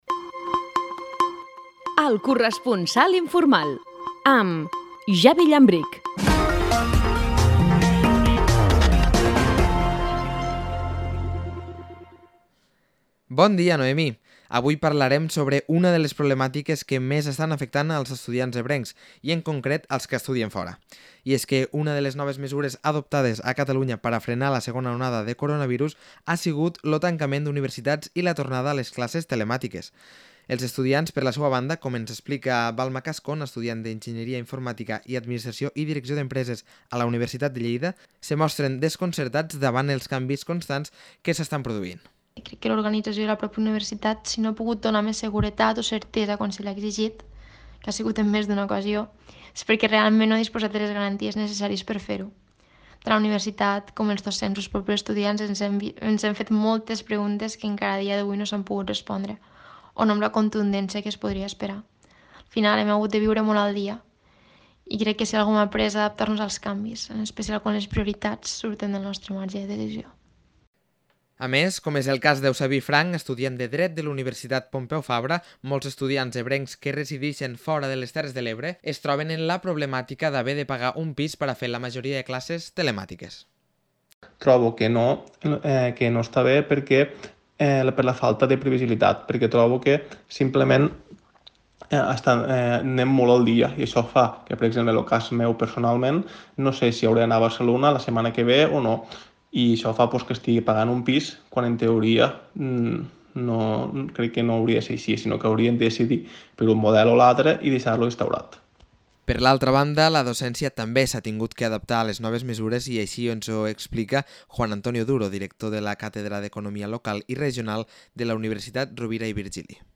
Avui estrenem El corresponsal informal, un espai de petits reportatges on es tractaran diferents problemàtiques del territori ebrenc. En aquesta primera entrega s’ha parlat d’una de les problemàtiques que més estan afectant els estudiants ebrencs, en concret, als que estudien fora.